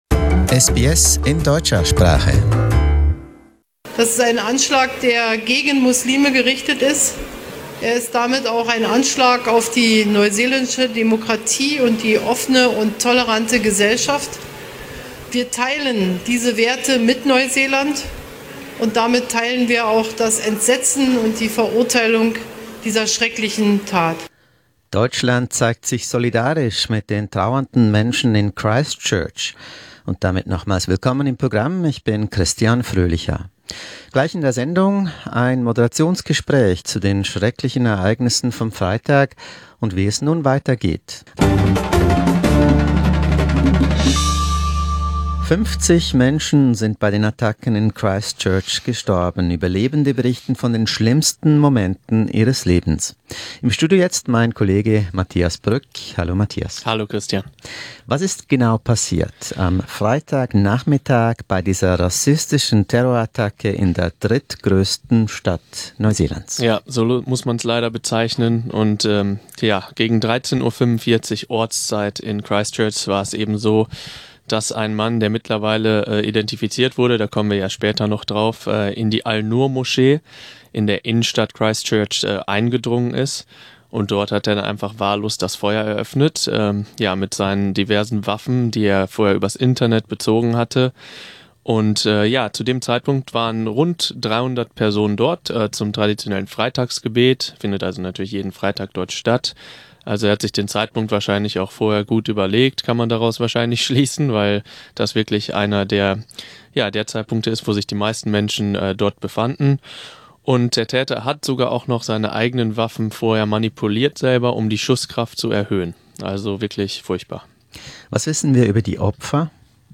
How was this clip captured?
New Zealand is mourning the violent murder of 50 of its people, following a terror attack by a white supremacist at two mosques in Christchurch. We discuss the massacre and its fallout in a candid studio talk.